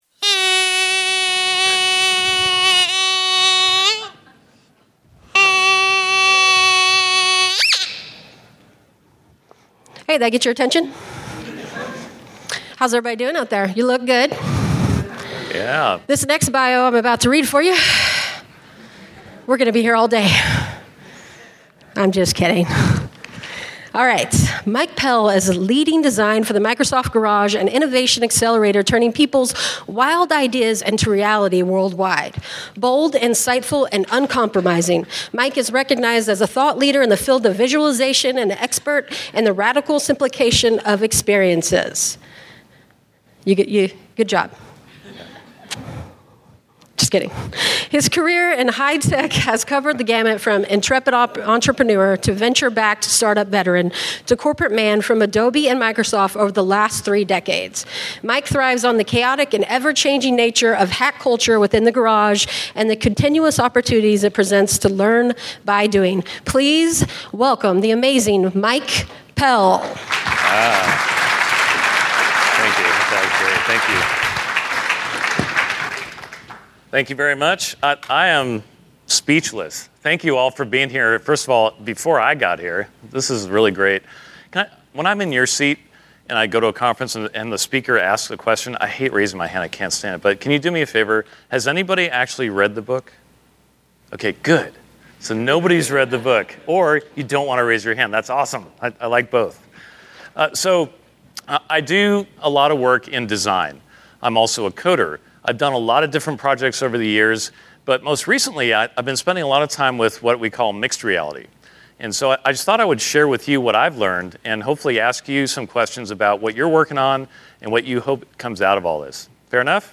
SXSW book reading